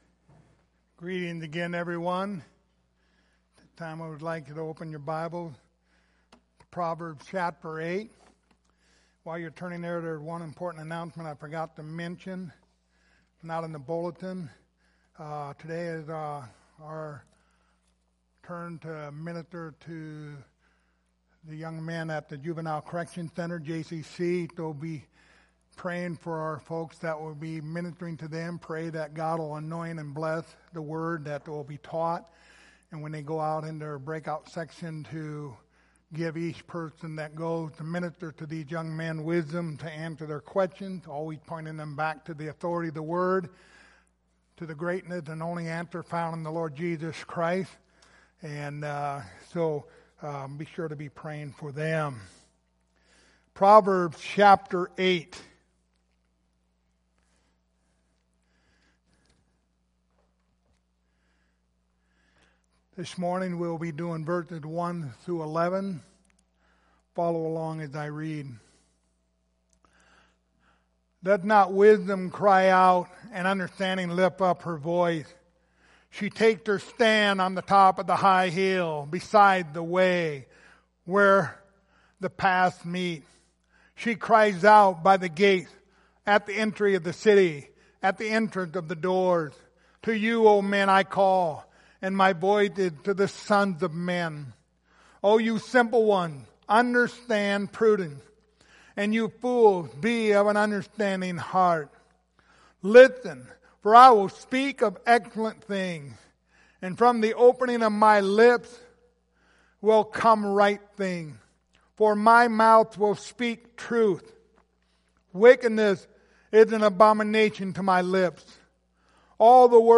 The Book of Proverbs Passage: Proverbs 8:1-11 Service Type: Sunday Morning Topics